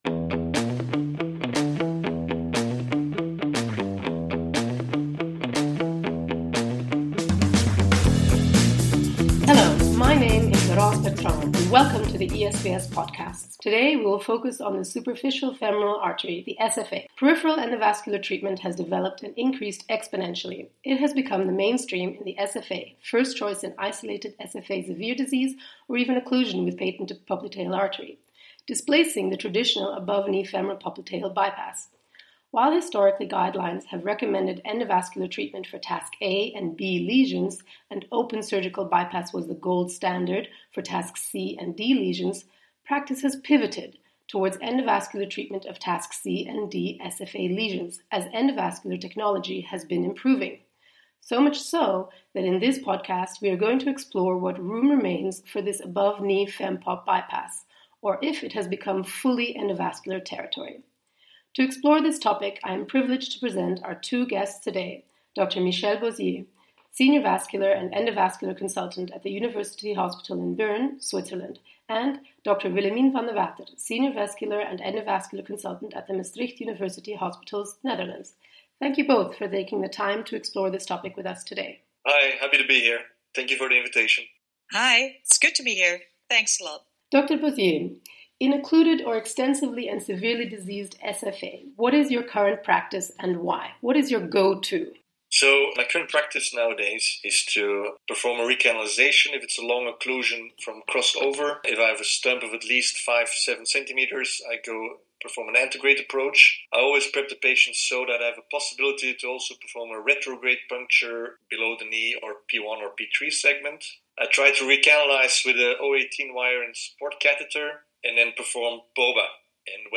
Join us in this expert interview